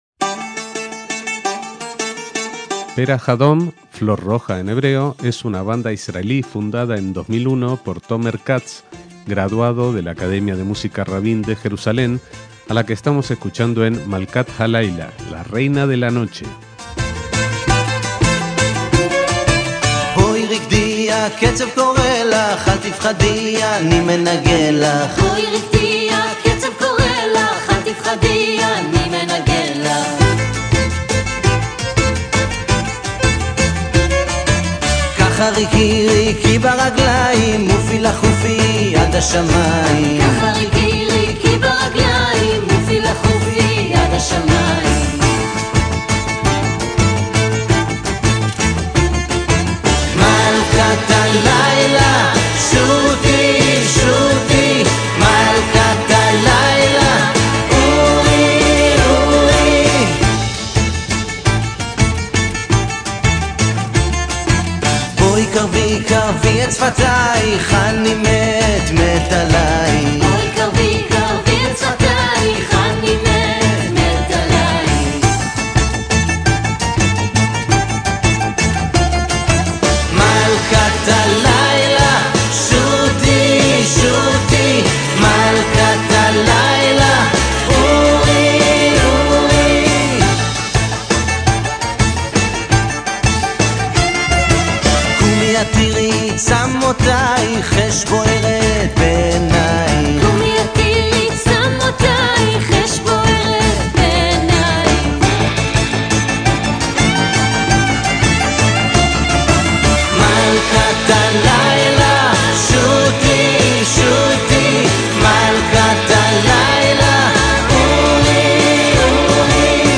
PONLE NOTAS - Perach Adom (Flor roja) es una banda israelí que toca música del estilo tradicional griego llamada rembetiko, así como canciones en judeoespañol y hebreo, recorriendo los caminos sonoros de judíos de Salónica como Moshe Kazis, Tzadik Gershon, las cantantes judías Stella Haskil y Roza Eskenazi.